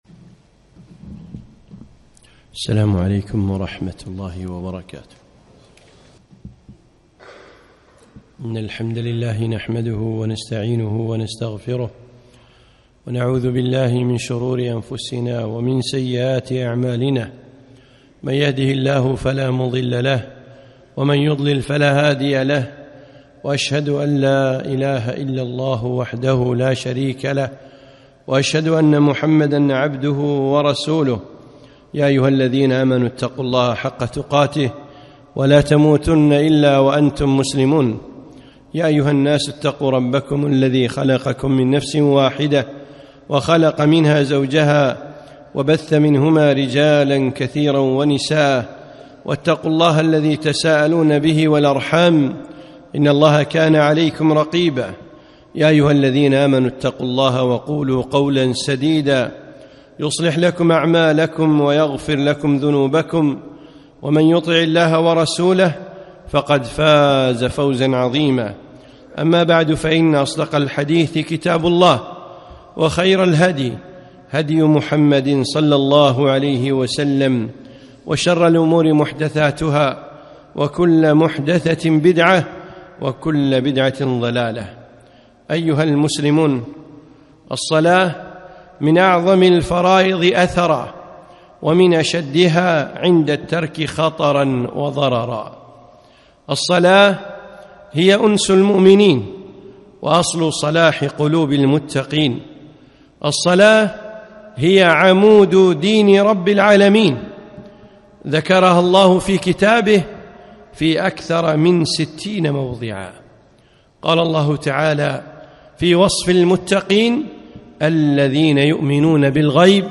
خطبة - الصلاة وخشوعها